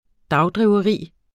Udtale [ ˈdɑwˌdʁiːwʌˌʁiˀ ]